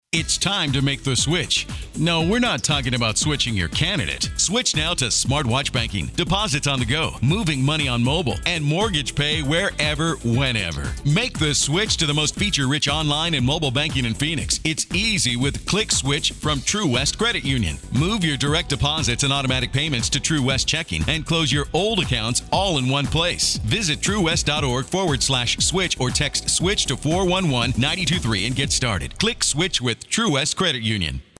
Radio Samples